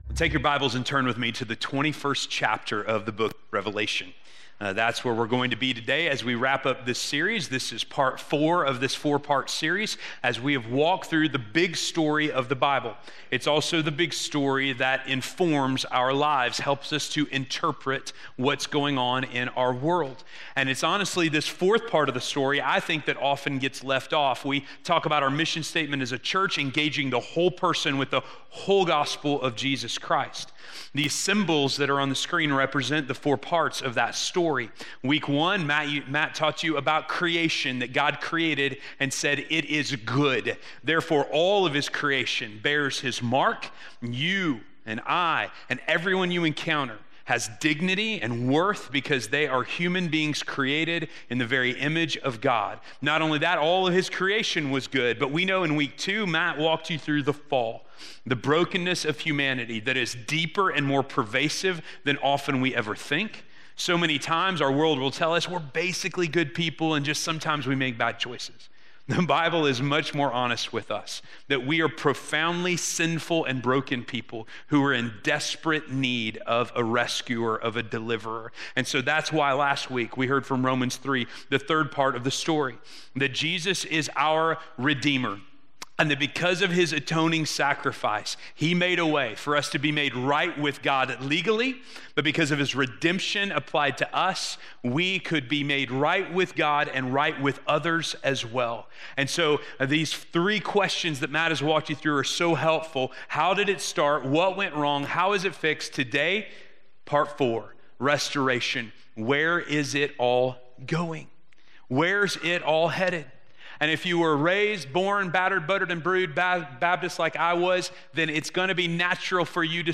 The Story of Restoration - Sermon - West Franklin